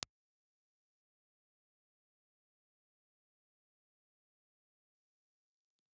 Ribbon
Impulse Response file of the RCA 74A ribbon microphone.
RCA_74A_IR.wav